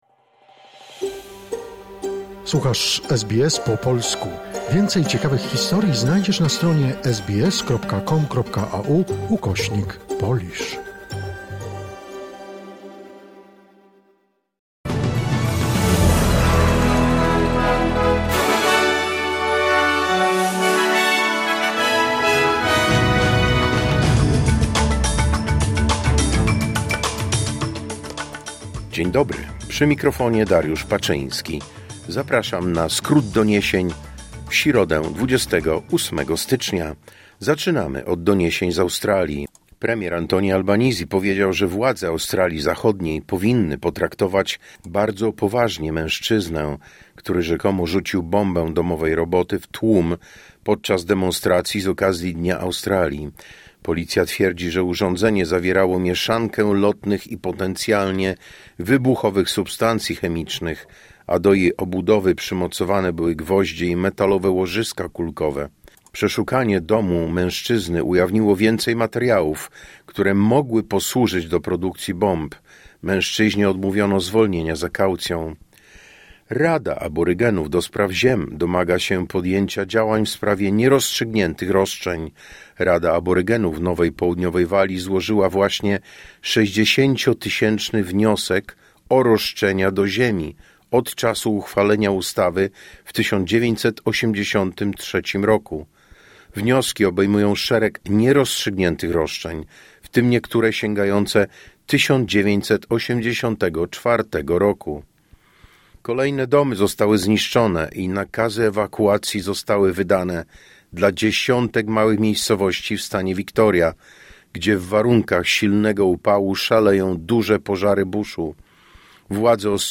Wiadomości 28 stycznia 2026 SBS News Flash